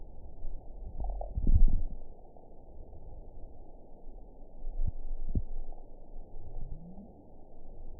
event 922003 date 12/25/24 time 01:25:02 GMT (11 months, 1 week ago) score 7.20 location TSS-AB03 detected by nrw target species NRW annotations +NRW Spectrogram: Frequency (kHz) vs. Time (s) audio not available .wav